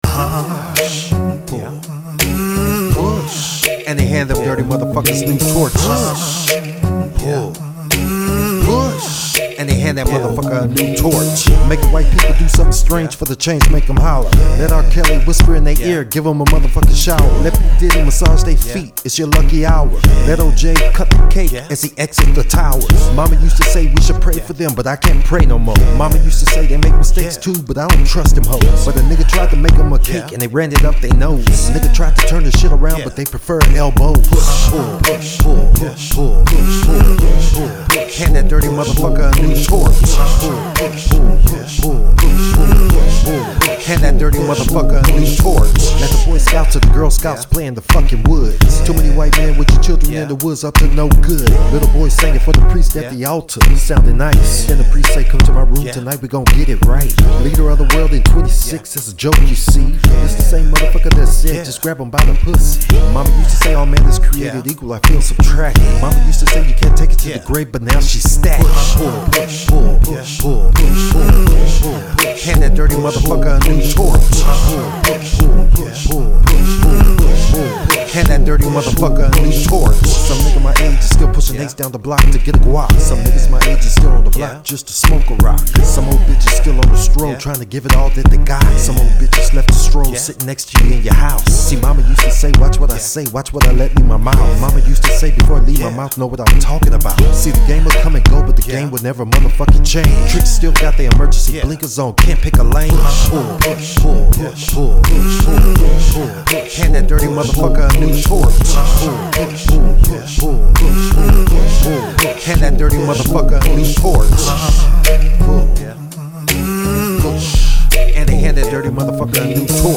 THIS IS A NEW ONE, NO WRITING